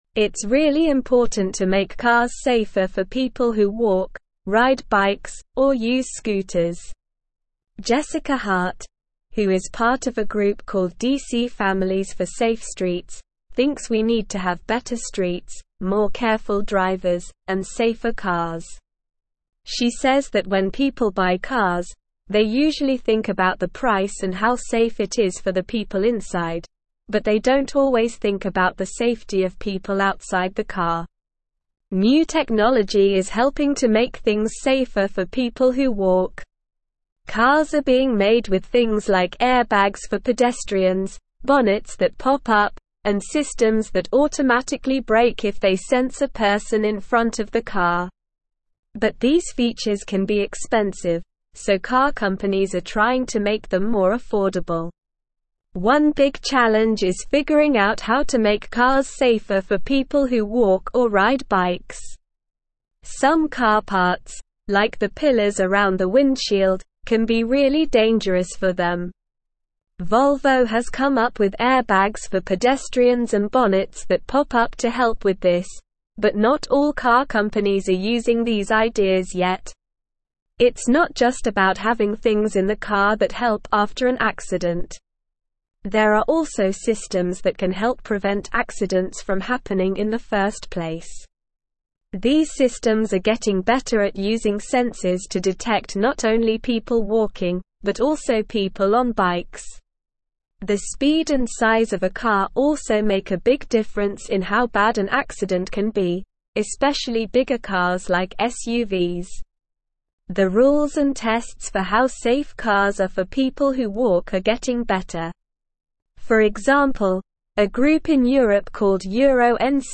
Slow
English-Newsroom-Upper-Intermediate-SLOW-Reading-Advocating-for-Safer-Streets-Protecting-Vulnerable-Road-Users.mp3